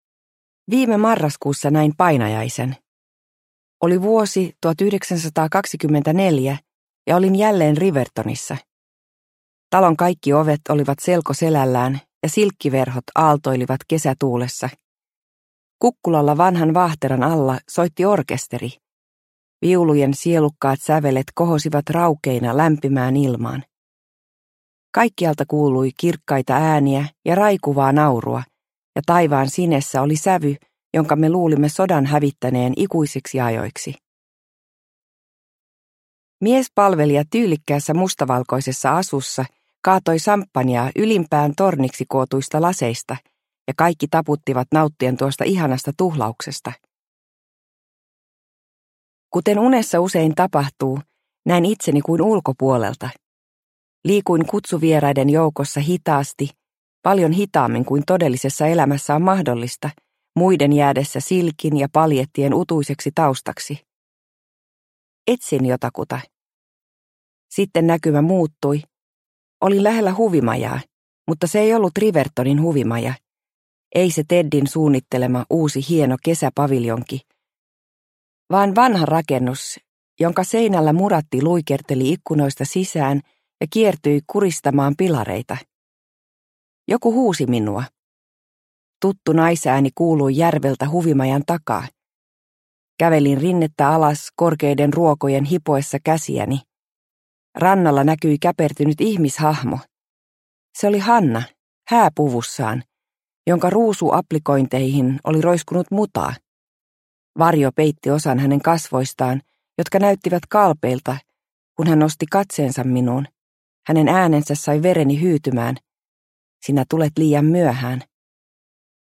Paluu Rivertoniin – Ljudbok – Laddas ner